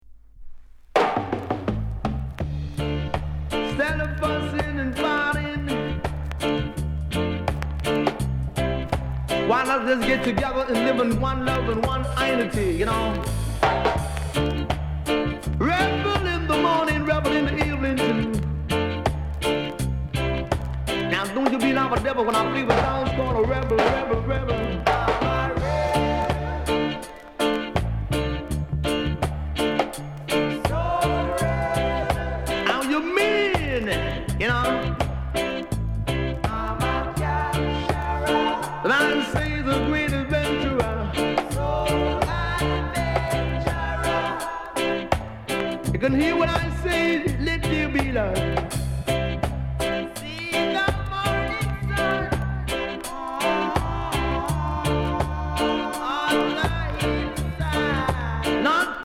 DEE JAY CUT